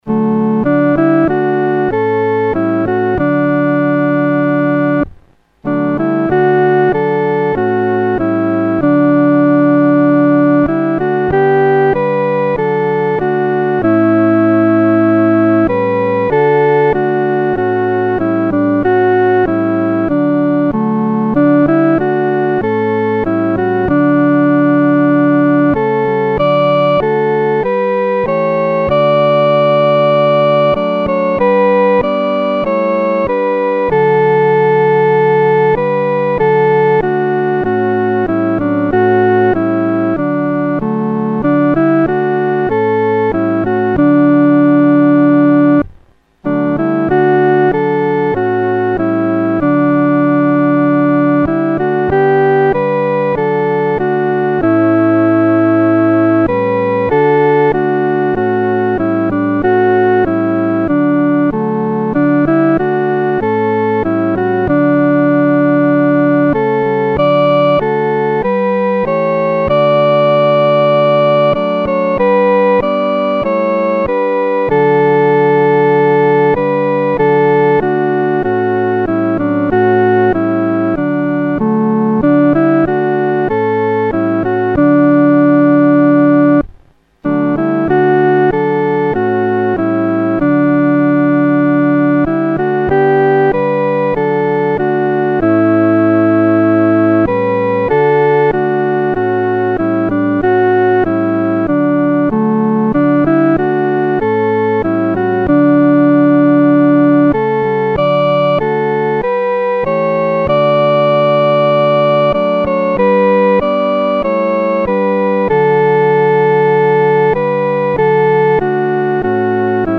伴奏
女高